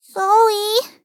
Worms speechbanks
stupid.wav